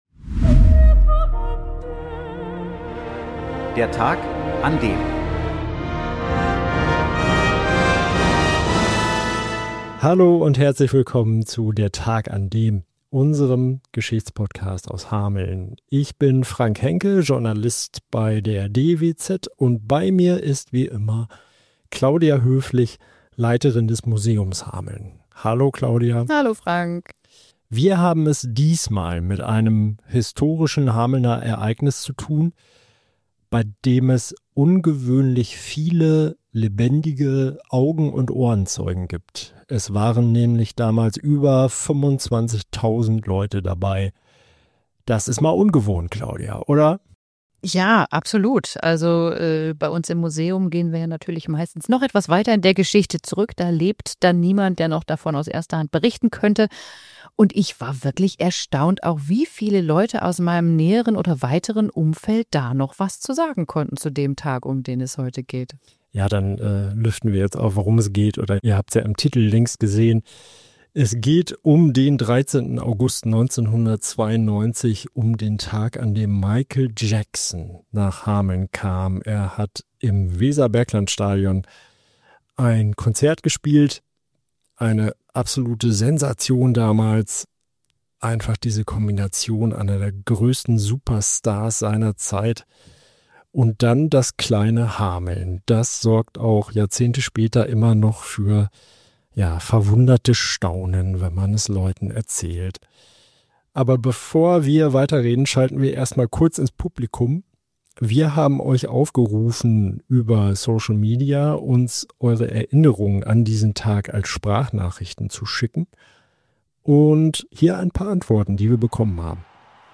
Das Podcast-Duo hat recherchiert und lässt Zeitzeugen zu Wort kommen.